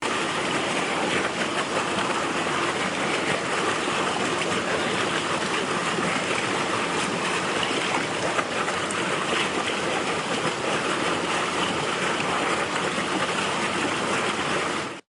Rushing Rapids
Rushing Rapids is a free nature sound effect available for download in MP3 format.
Rushing Rapids.mp3